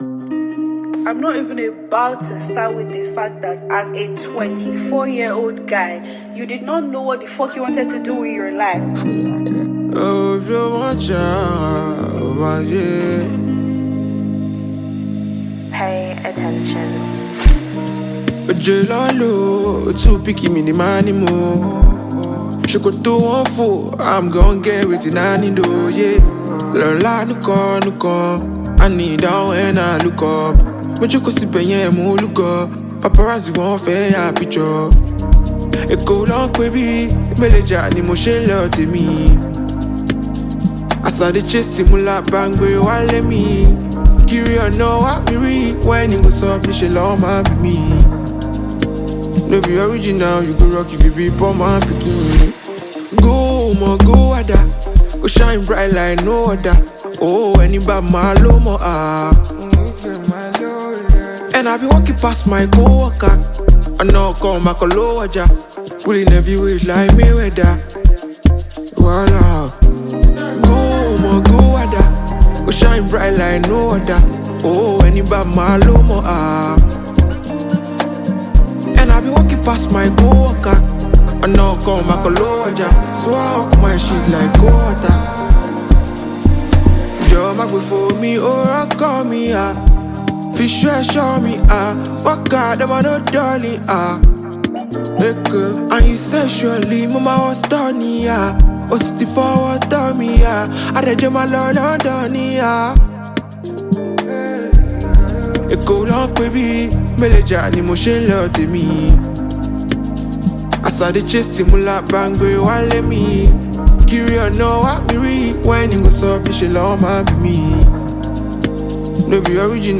Afro-Beat